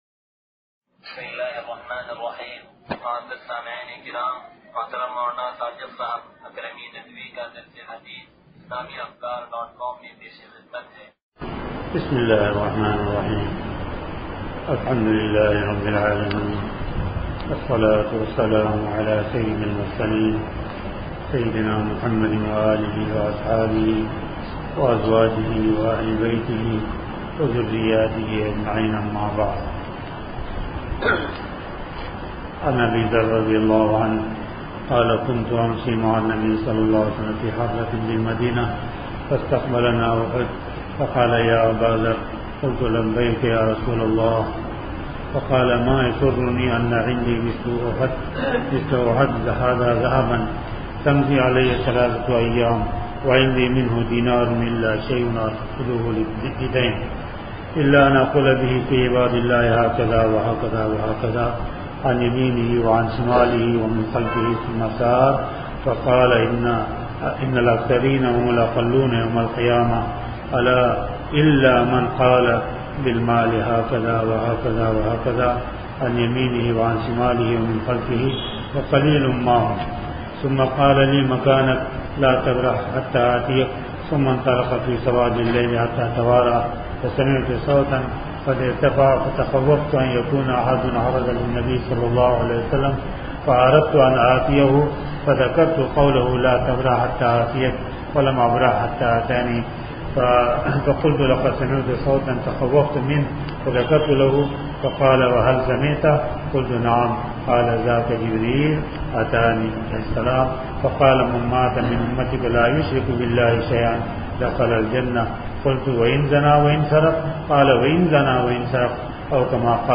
درس حدیث نمبر 0495